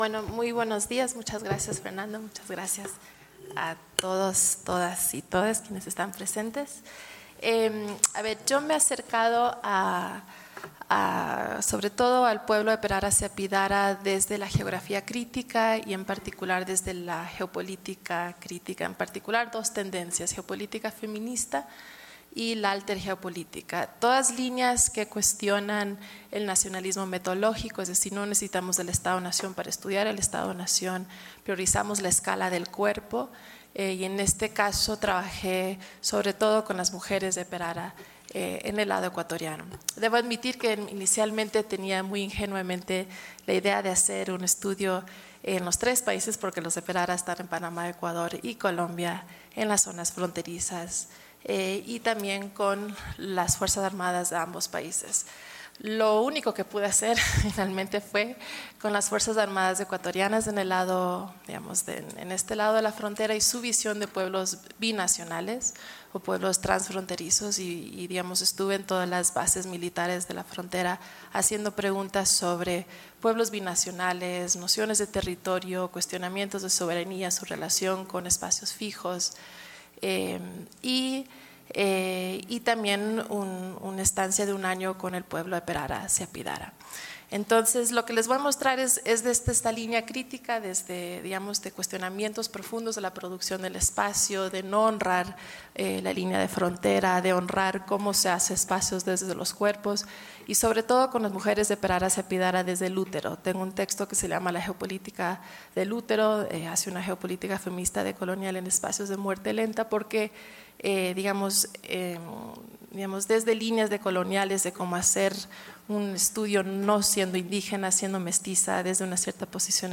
Seminario internacional Hacia la creación de un espacio transfronterizo de desarrollo en la frontera Ecuador – Colombia
Panel 1: Pueblos indígenas transfronterizos